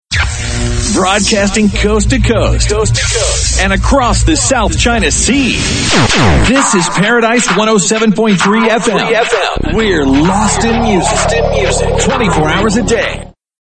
TOP 40